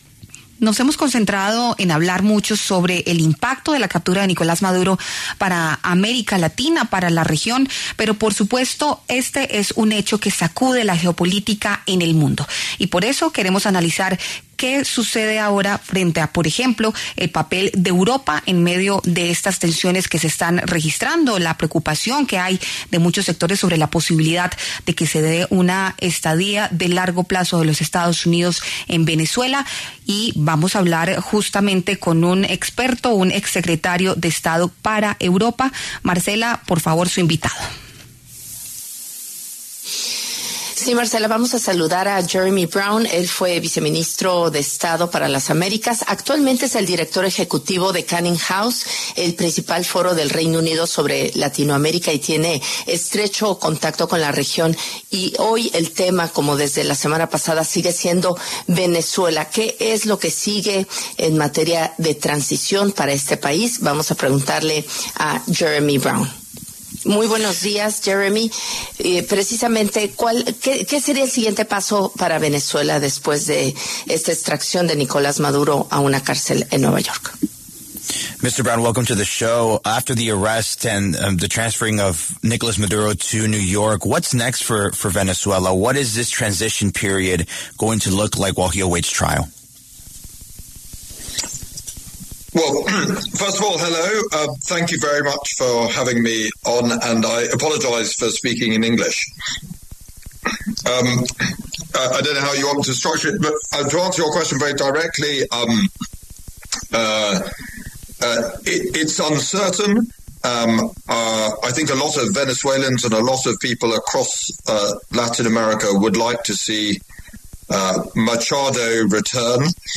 Jeremy Browne, exsecretario de Estado británico para las Américas, habló en La W sobre el escenario que viene para Venezuela tras la captura de Nicolás Maduro el pasado 3 de enero.